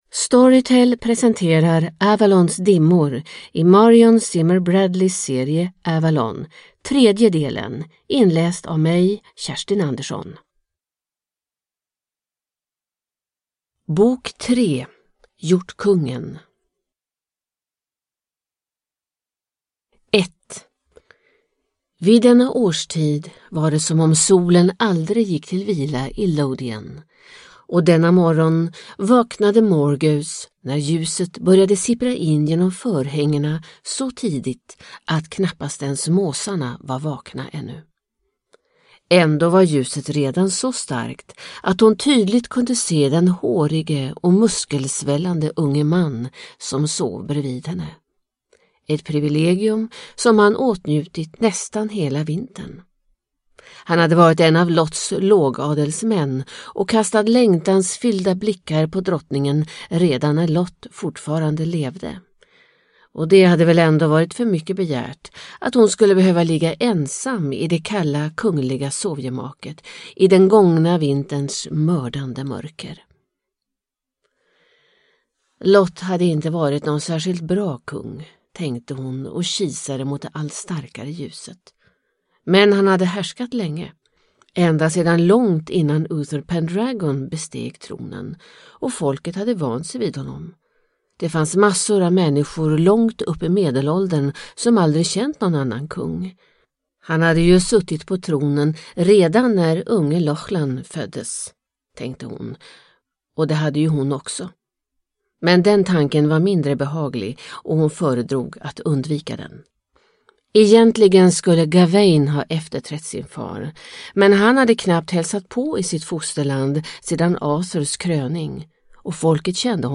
Avalons dimmor – del 3 – Ljudbok – Laddas ner